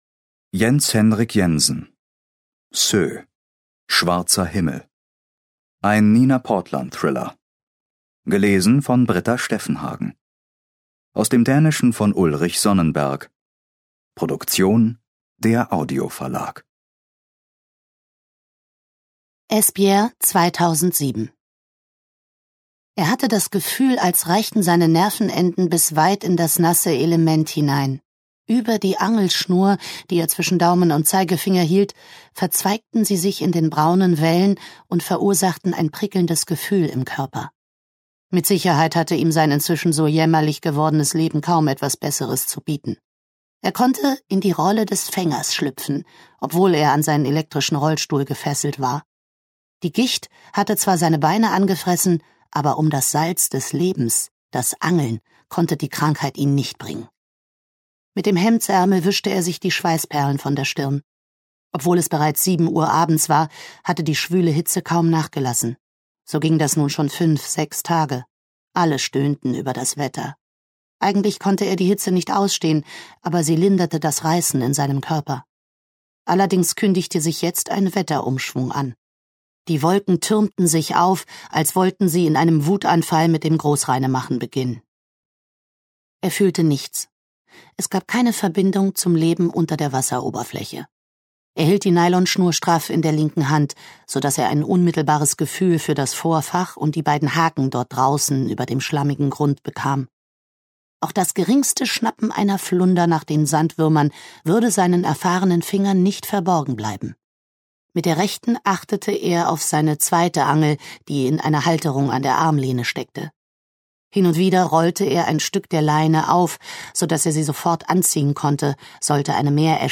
Hörbuch: SØG.
Ungekürzte Lesung